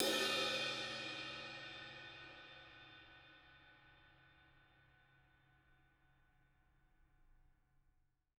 R_B Crash A 02 - Room.wav